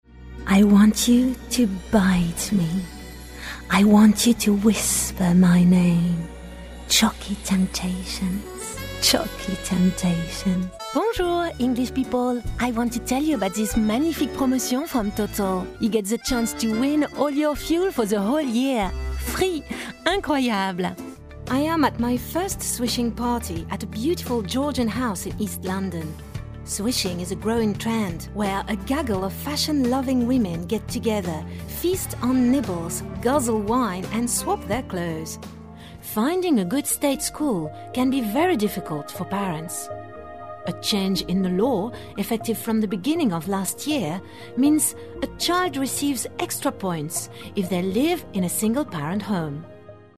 franzĂ¶sische Sprecherin.
She own a recording studio of good quality (Neumann microphone, iso-booth, ISDN).
Sprechprobe: Sonstiges (Muttersprache):
Native female French voice talent.